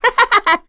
Chrisha-Vox_Happy2_kr.wav